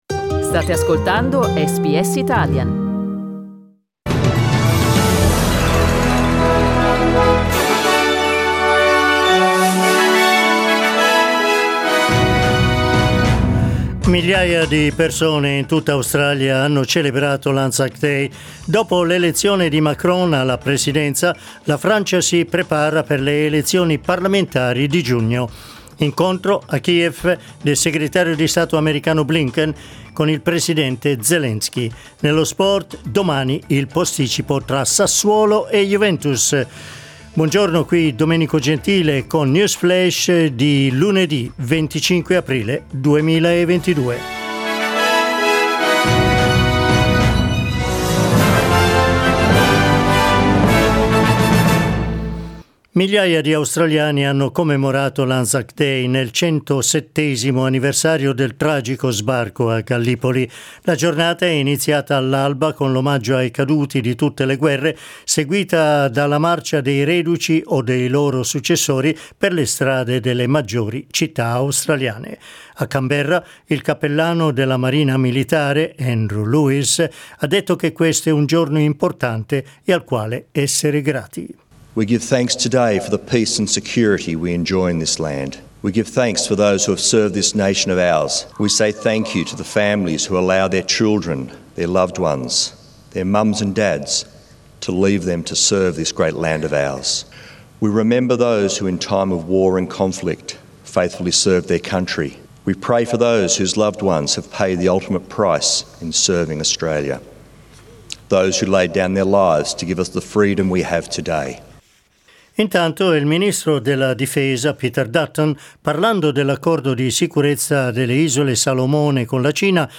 News flash lunedì 25 aprile 2022